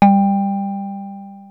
JUP.8 G3   3.wav